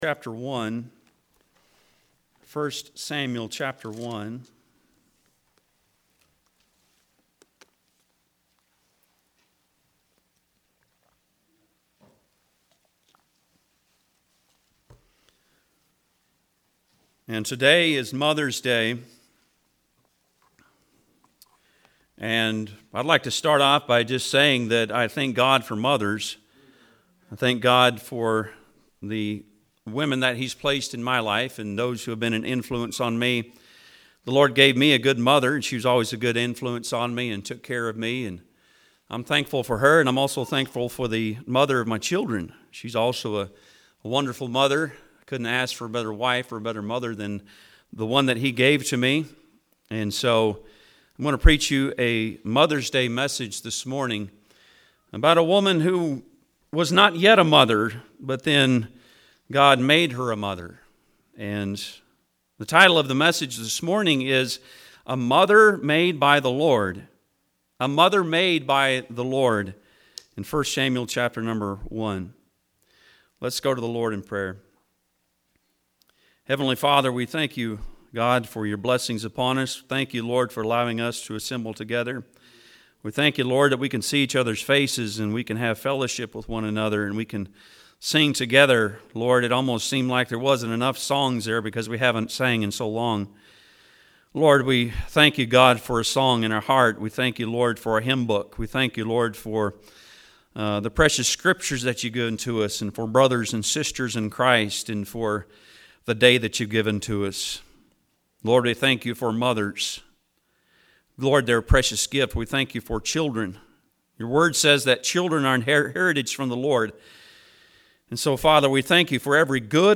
I Samuel 1:1-18 Service Type: Sunday am Bible Text